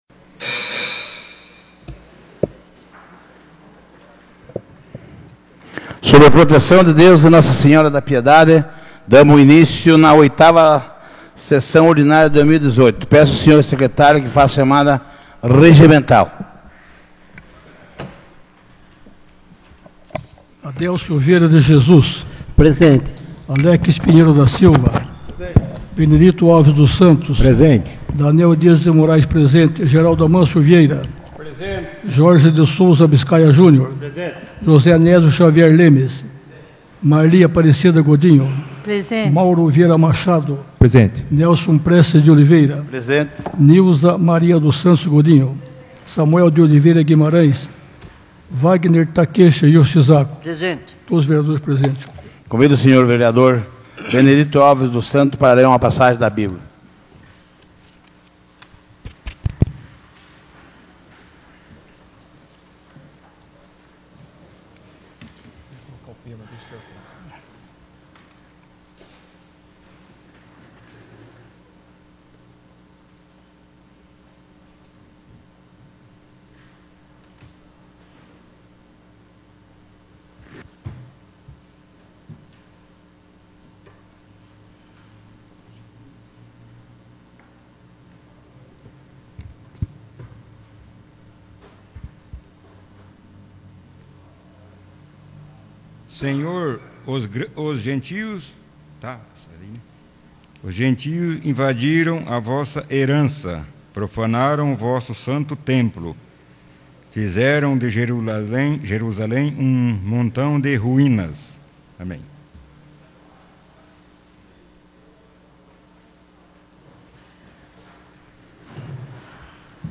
8ª Sessão Ordinária de 2018 — Câmara Municipal de Piedade